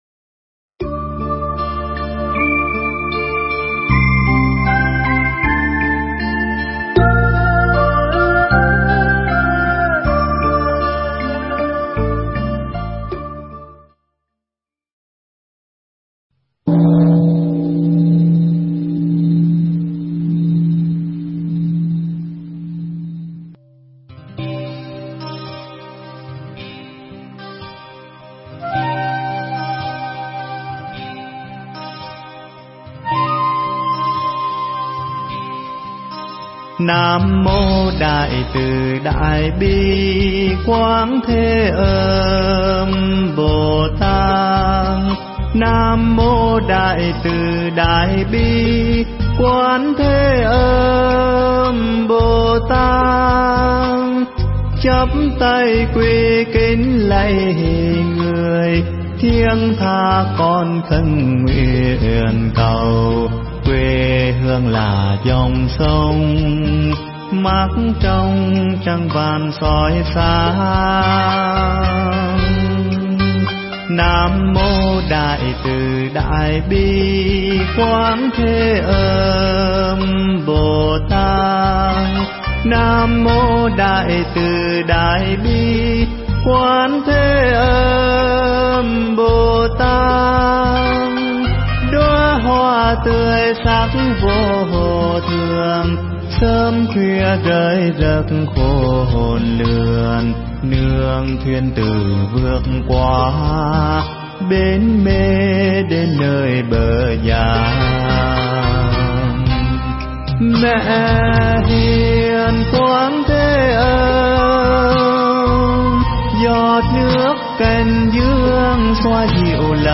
Nghe Mp3 thuyết pháp Mắt Thương Nhìn Đời